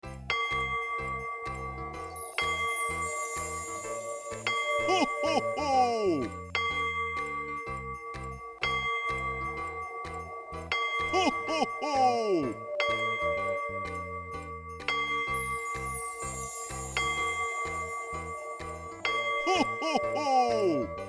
cuckoo-clock-10.wav